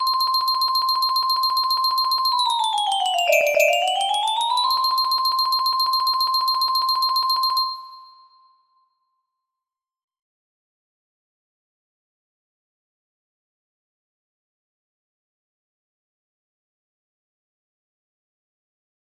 41 music box melody